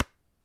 cardSlide1.ogg